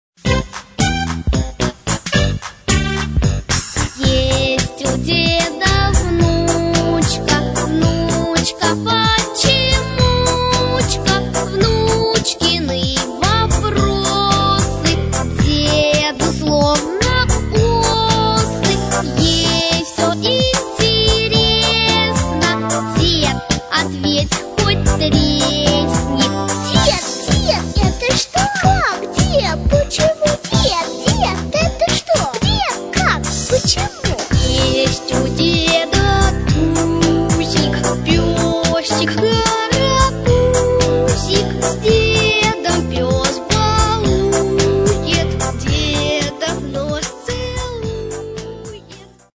совмещенные вместе размеры 7/4 и 7/8